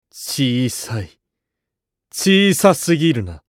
厨二病ボイス～戦闘ボイス～
【挑発2】